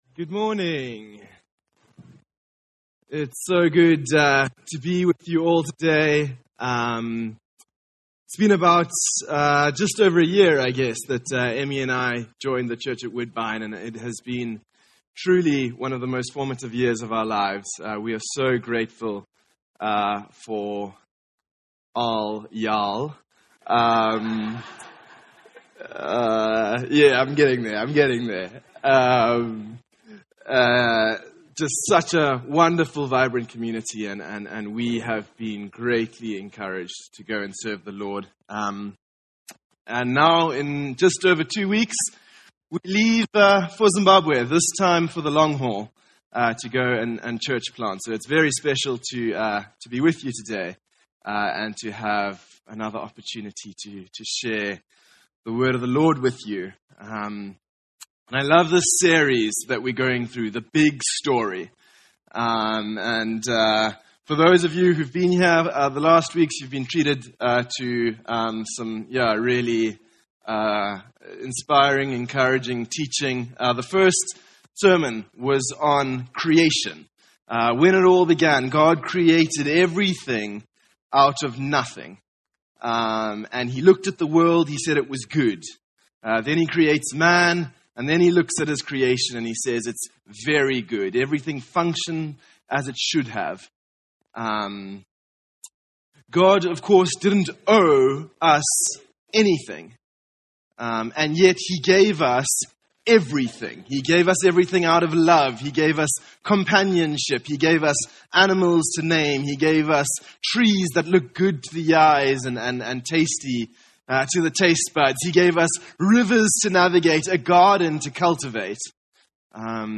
The Story of Redemption - Sermon - Woodbine